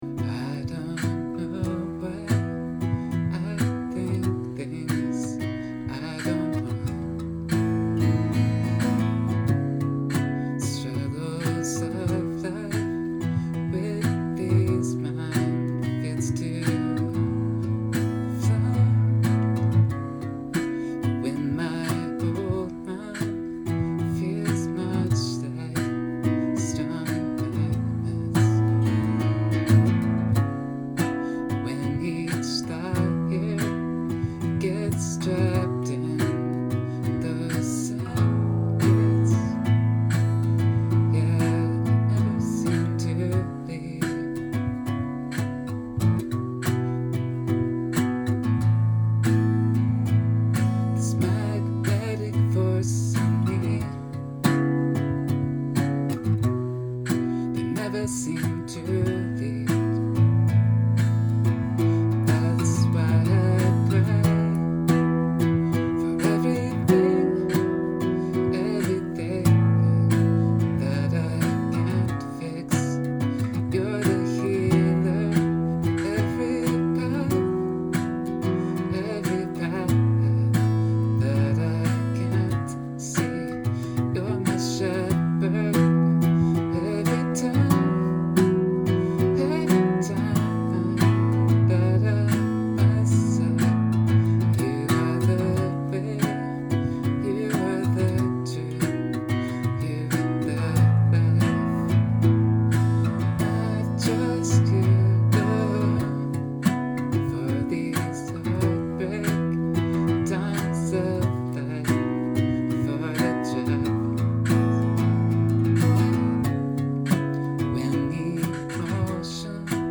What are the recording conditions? practice eicf-practice.mp3